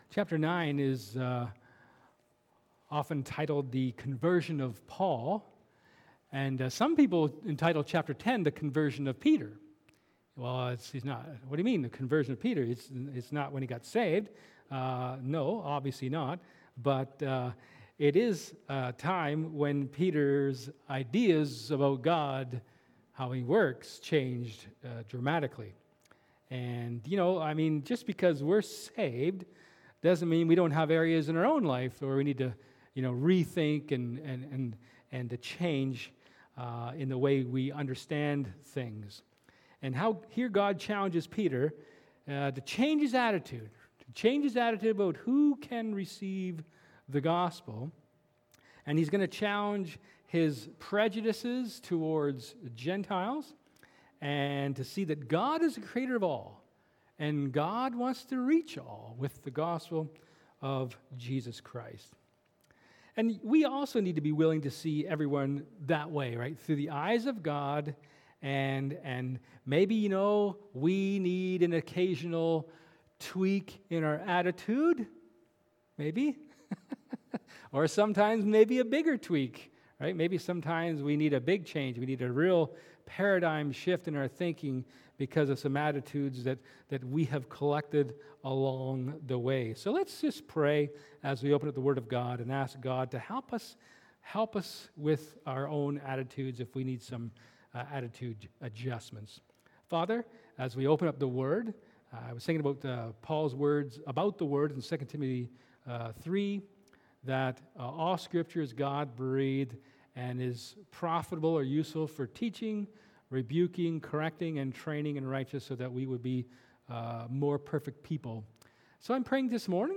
1 Corinthians 7:10-24 Service Type: Sermon